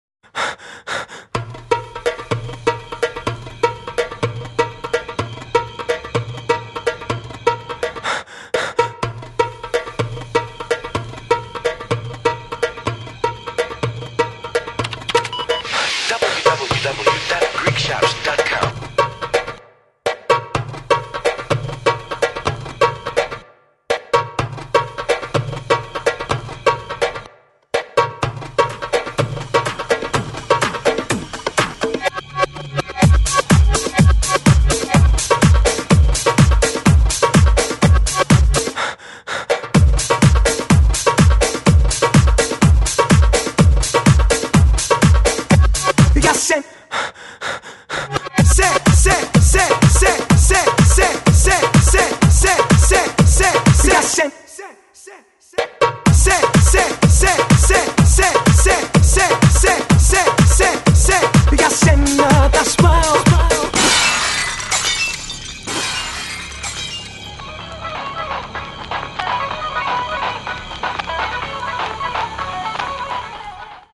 Super chart hits remixed for great dance hits.